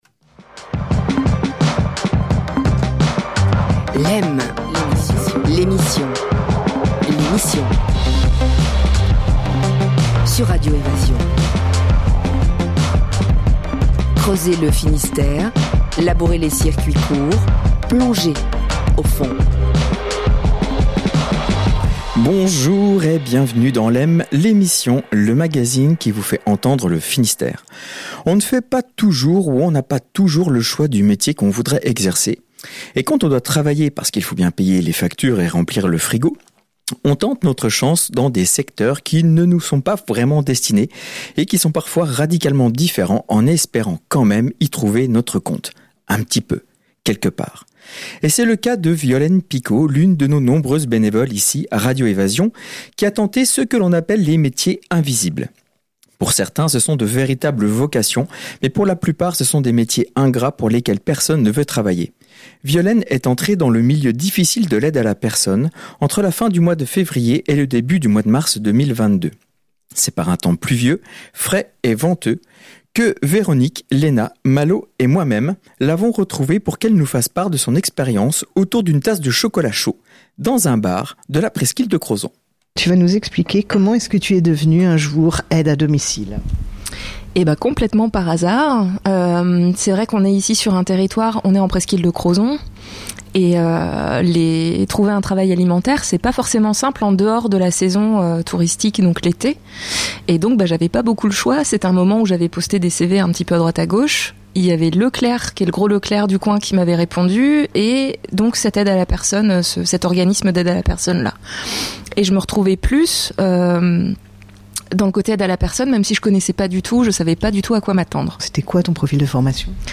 Les métiers de l’invisible : témoignage d’une aide à domicile.